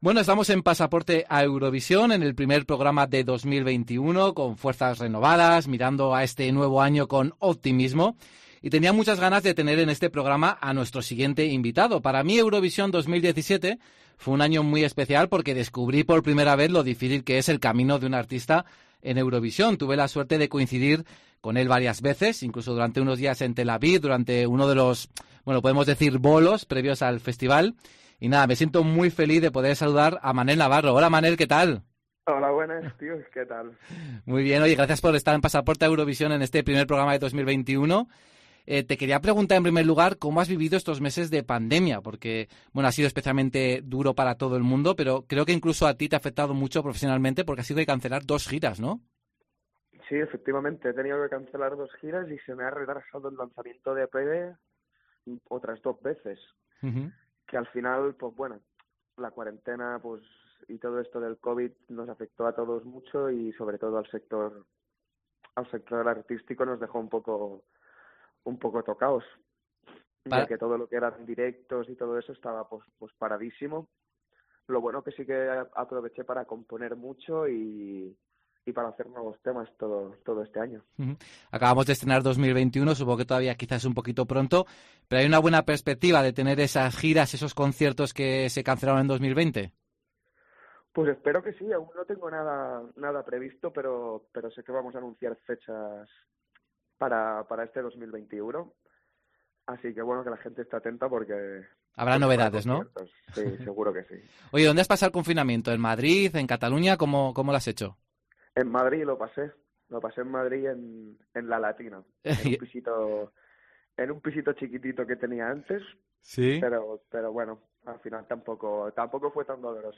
La voz del artista catalán suena abatida, en ocasiones resignada.